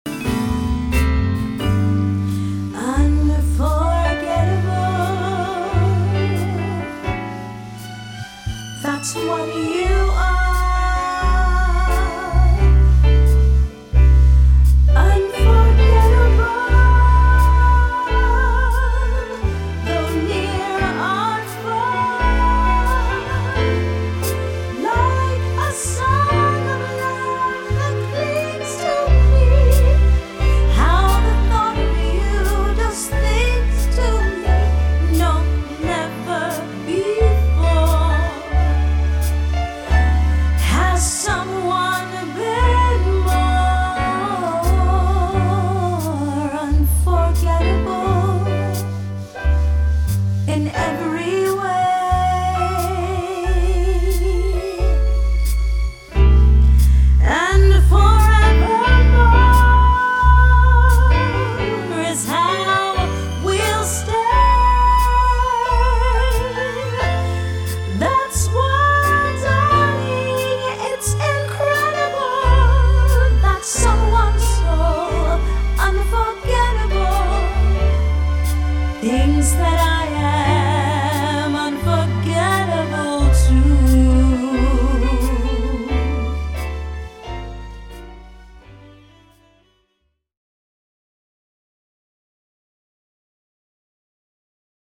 Wedding Singer